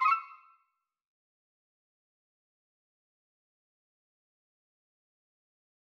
error_style_4_003.wav